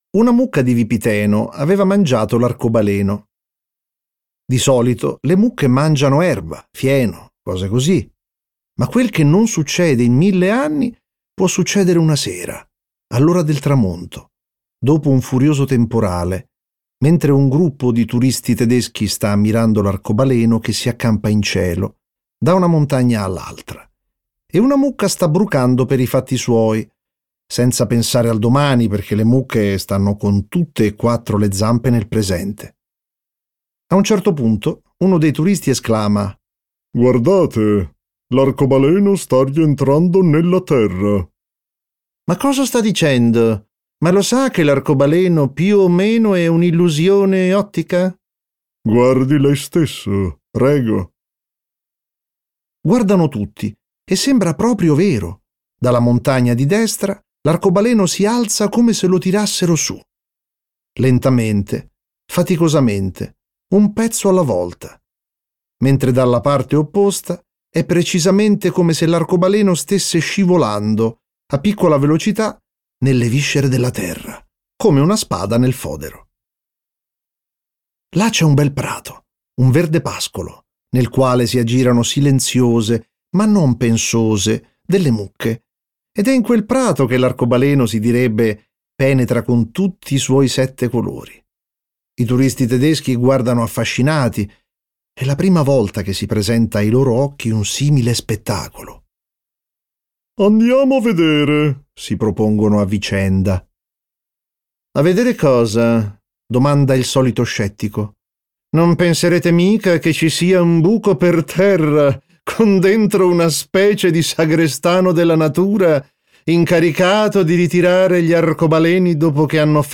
letto da Max Paiella
Versione audiolibro integrale